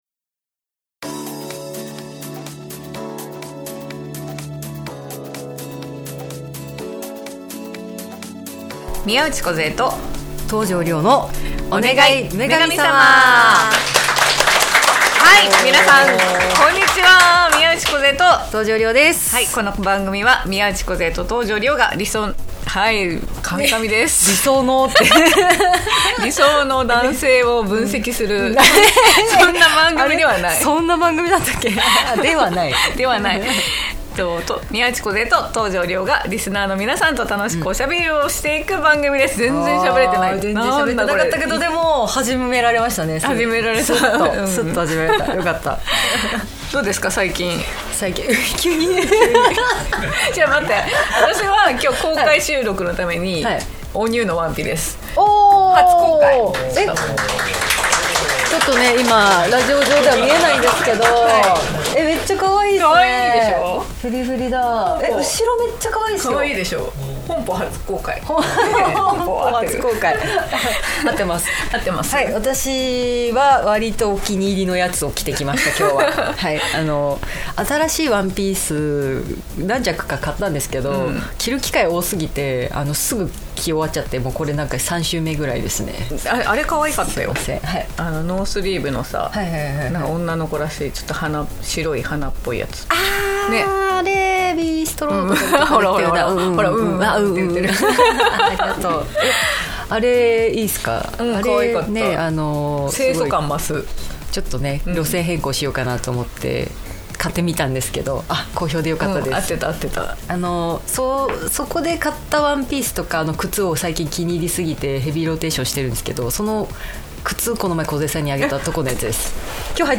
いつも沢山の応援をいただき2回目の公開収録が開催されました！おふたりの今年の夏の思い出とは！？